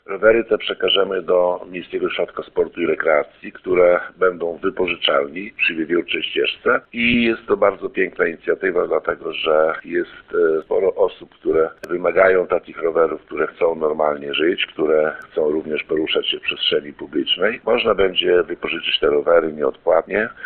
O tym mówi burmistrz Wacław Olszewski.